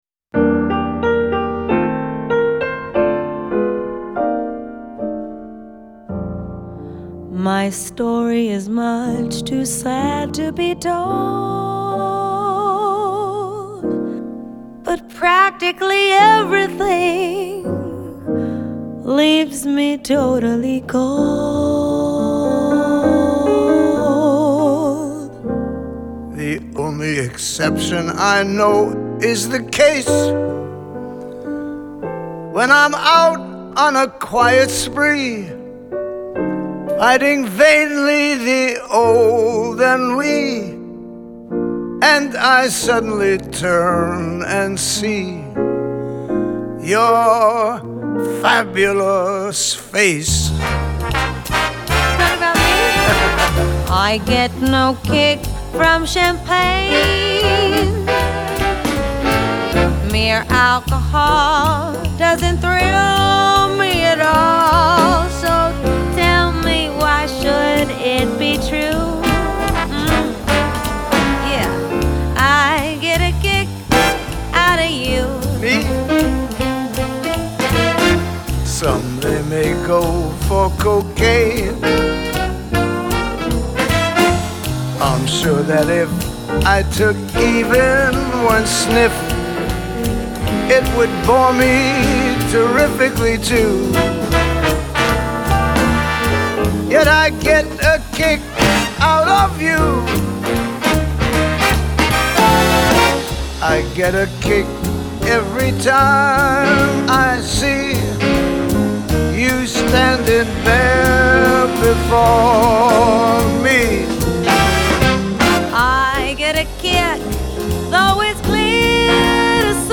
Genre : Vocal Jazz